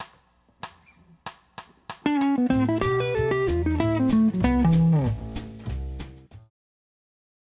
The first 4 examples are Am runs;